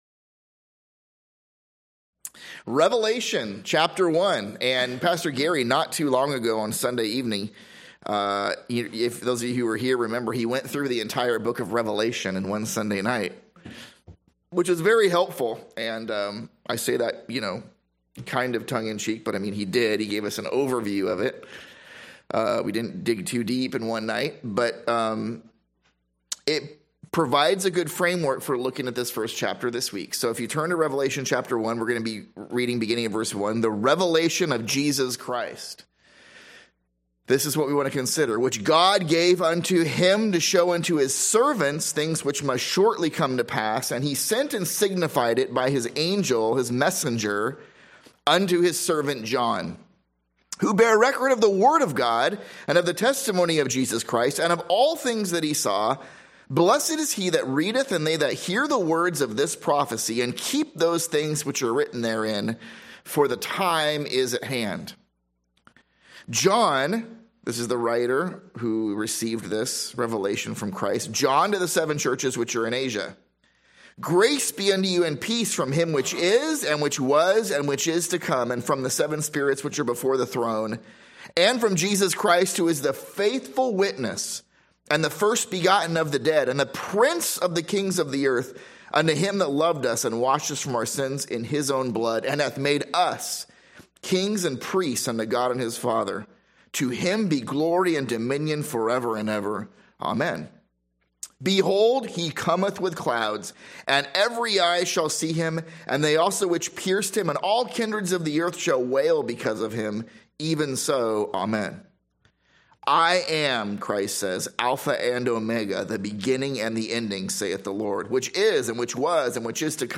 / A Sunday School series through the first three chapters of Revelation.